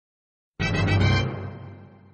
Sound_BuildingAddition.mp3